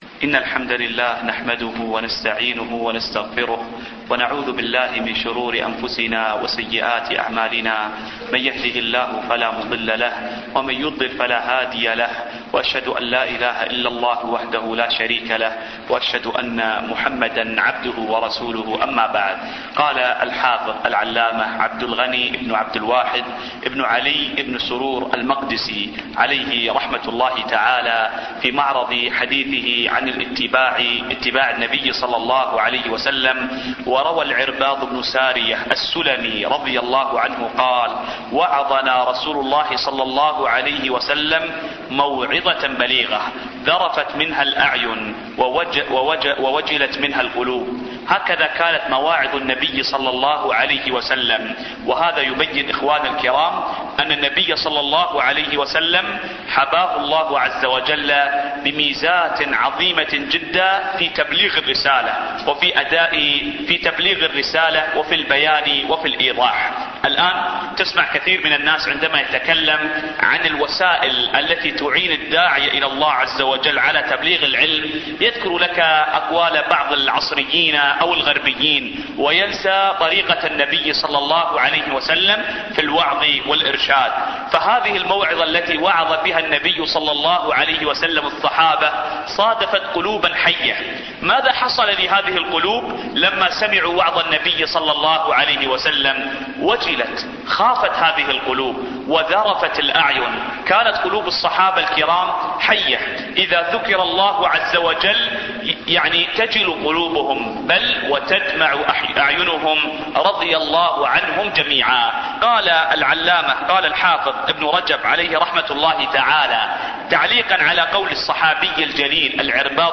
الدرس الخامس والعشرون